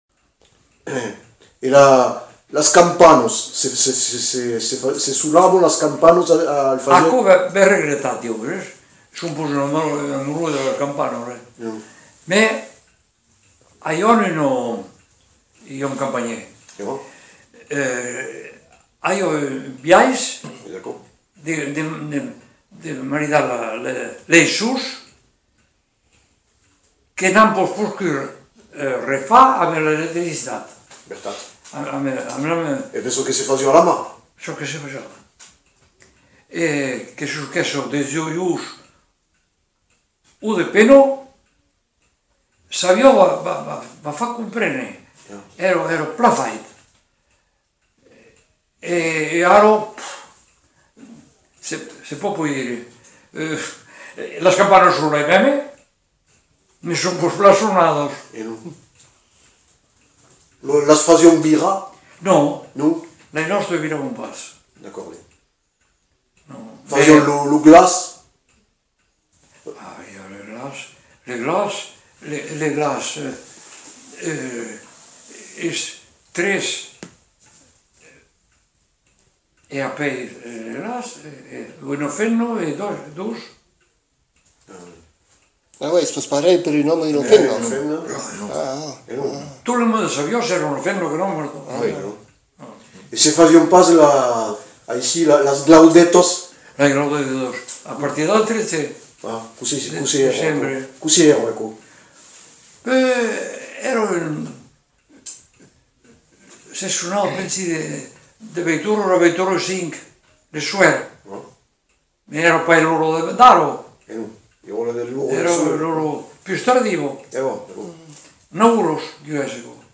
Lieu : Le Faget
Genre : témoignage thématique